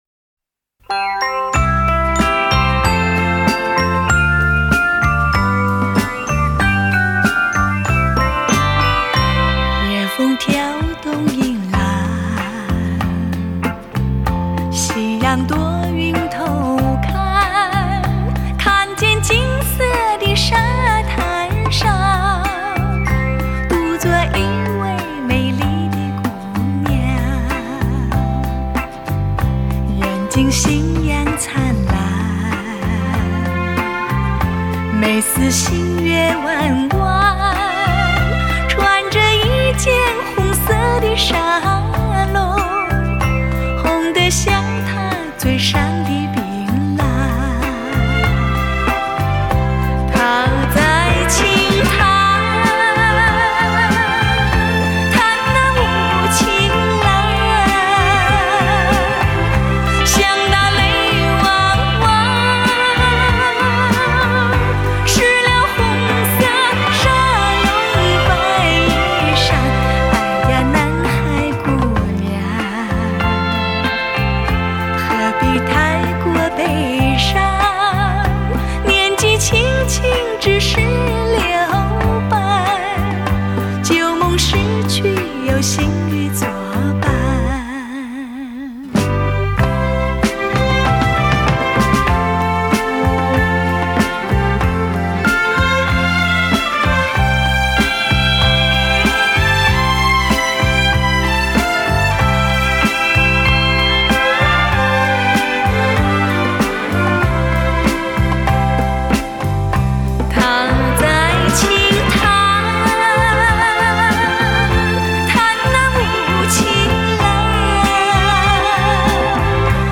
汽车音乐：极富视听效果的发烧靓声，德国版HD高密度24bit数码录音。